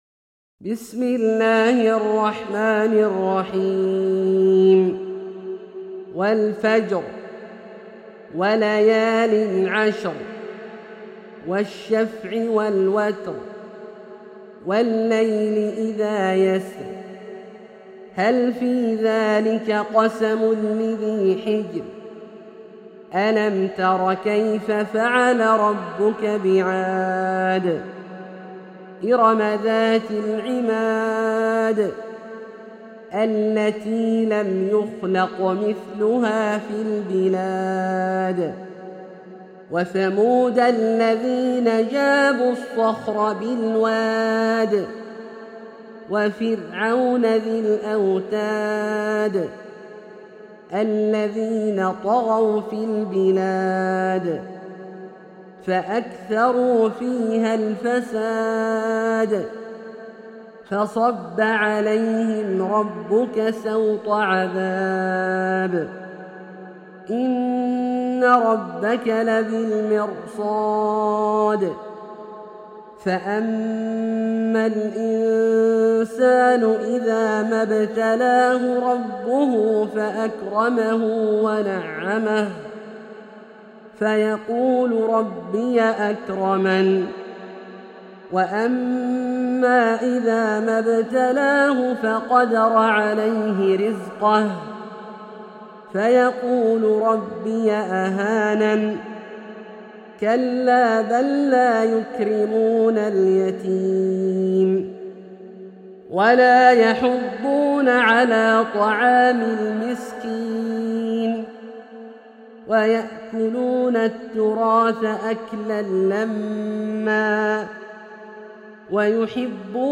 سورة الفجر - برواية الدوري عن أبي عمرو البصري > مصحف برواية الدوري عن أبي عمرو البصري > المصحف - تلاوات عبدالله الجهني